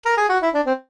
jingles-saxophone_00.ogg